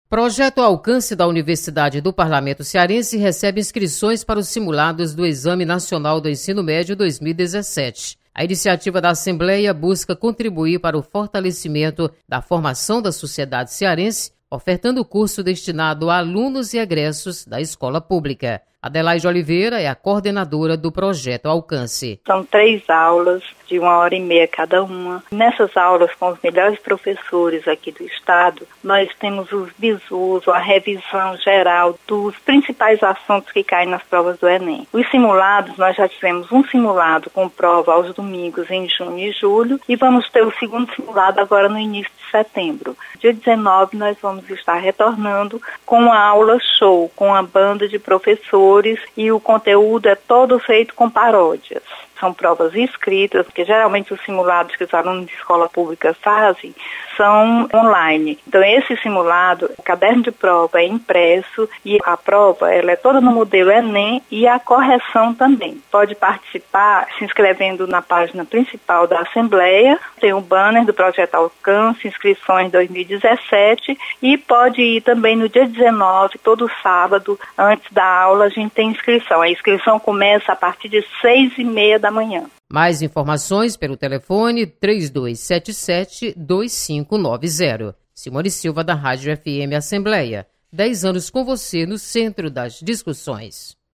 Assembleia recebe inscrições para simulado do Enem Repórter